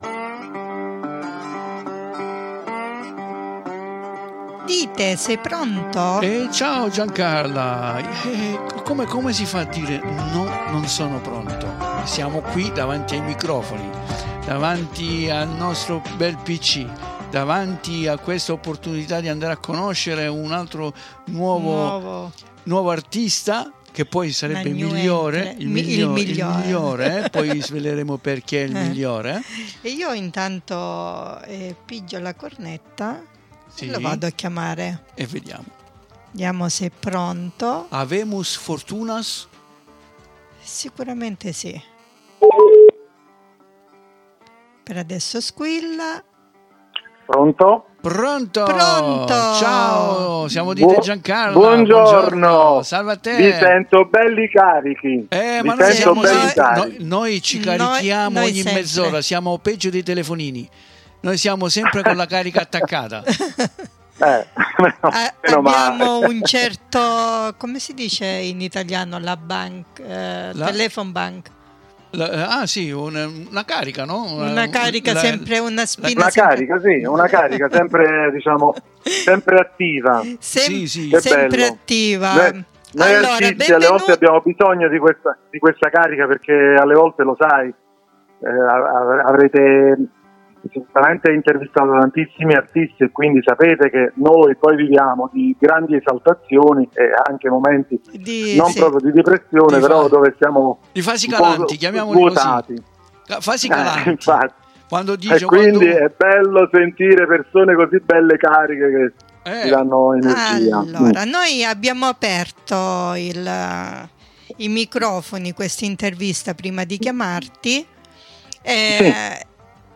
QUINDI VI AUGURIAMO UN BUON ASCOLTO DELL'INTERVISTA CONDIVISA QUI IN DESCRIZIONE E VI ALLEGO INOLTRE UN LINK DI YOUTUBE DOVE POTRETE SCOPRIRE ALTRI SUOI LAVORI!